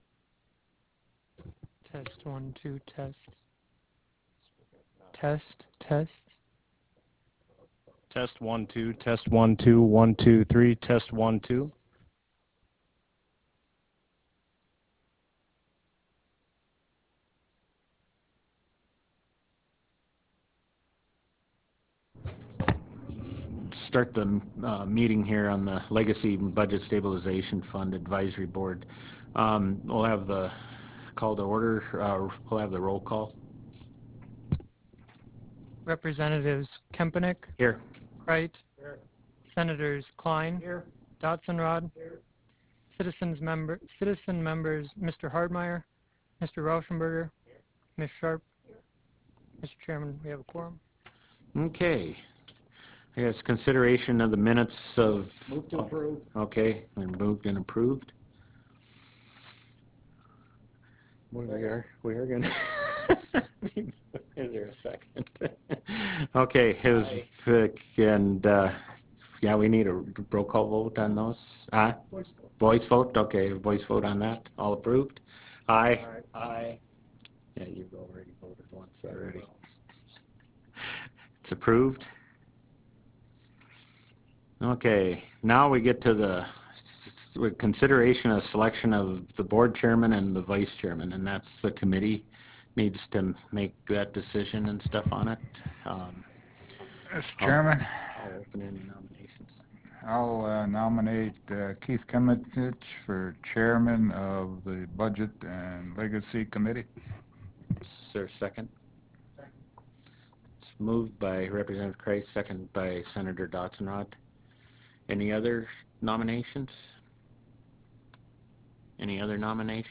Harvest Room State Capitol Bismarck, ND United States